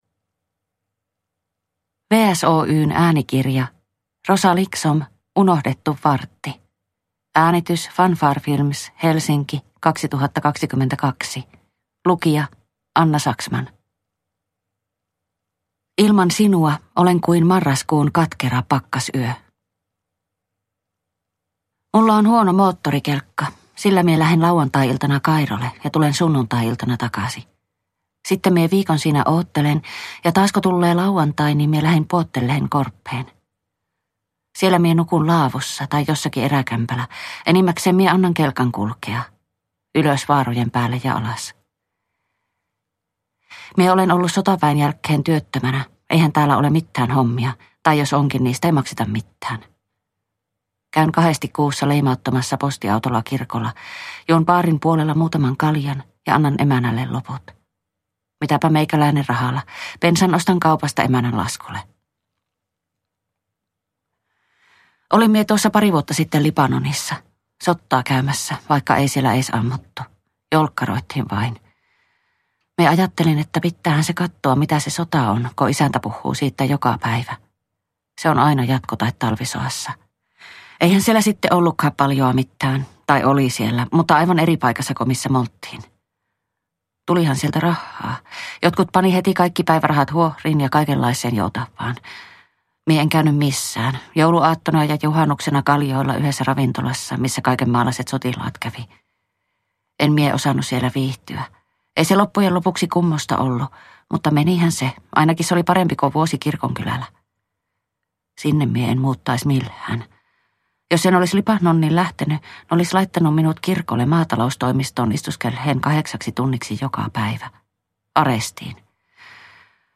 Unohdettu vartti – Ljudbok – Laddas ner